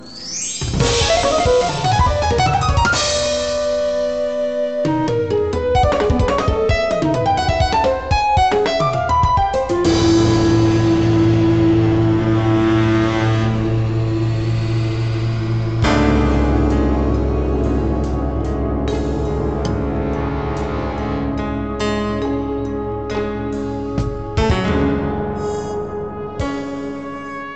Low quality (around 100 kbit/s) sample